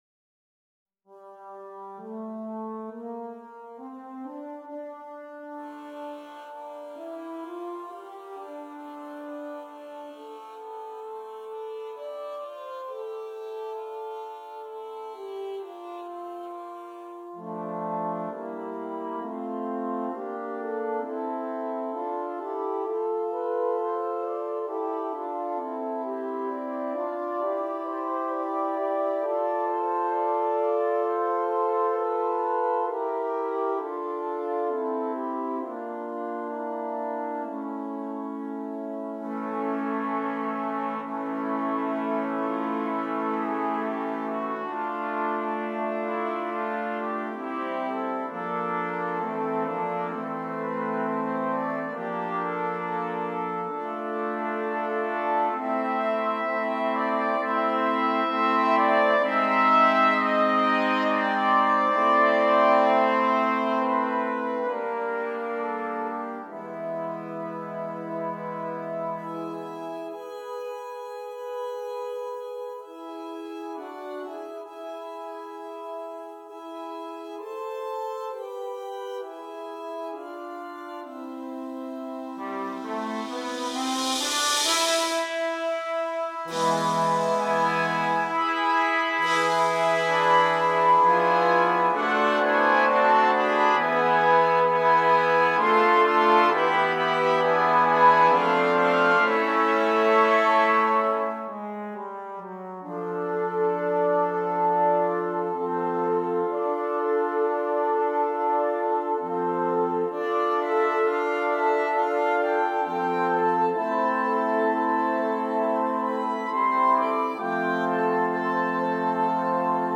10 Trumpets and Percussion